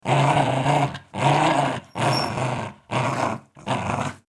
Dogs Growling 4 Fx Sound Effect Download: Instant Soundboard Button
Dog Sounds3,336 views